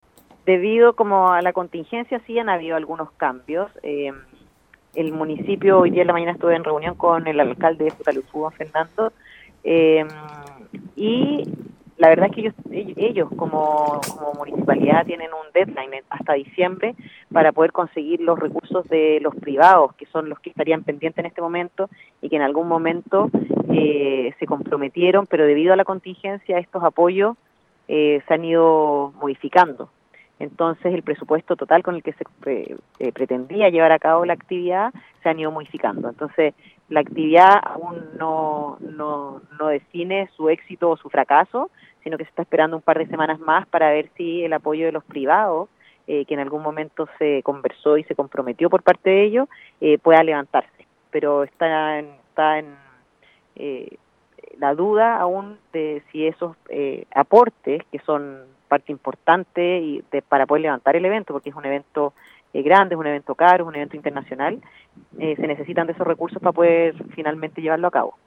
En el marco de su visita por la provincia de Palena, la Directora del Servicio Nacional de Turismo en la Región de Los Lagos, Paulina Ros, conversó con Radio Estrella del Mar, oportunidad en que señaló que el objetivo fue poder reunirse con actores ligados a la industria turística.